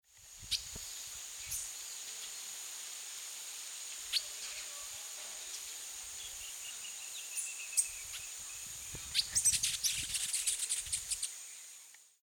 Frutero Azul (Stephanophorus diadematus)
Nombre en inglés: Diademed Tanager
Fase de la vida: Adulto
Localidad o área protegida: Delta del Paraná
Condición: Silvestre
Certeza: Observada, Vocalización Grabada